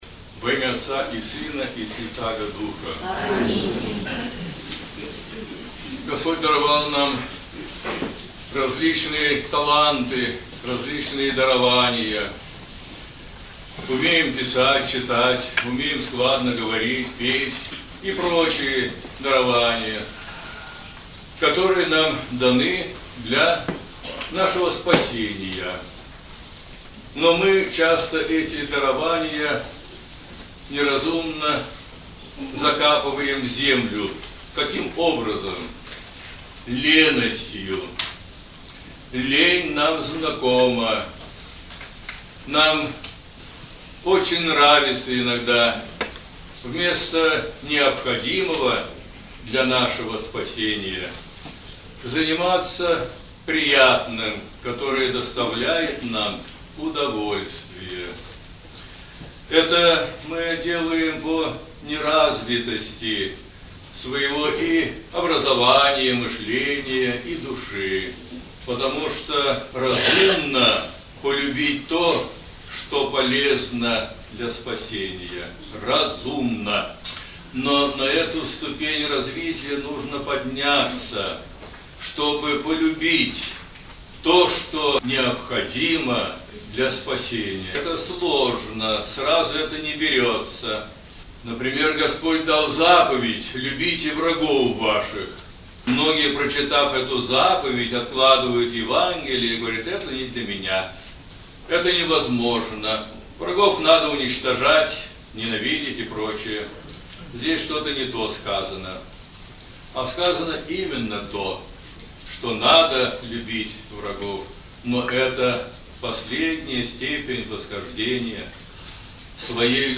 Проповеди (аудио) Полюбить полезное для спасения.